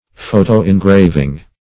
Photo-engraving \Pho`to-en*grav"ing\, n. [Photo- + engraving.]